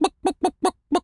pgs/Assets/Audio/Animal_Impersonations/chicken_cluck_bwak_seq_02.wav at master
chicken_cluck_bwak_seq_02.wav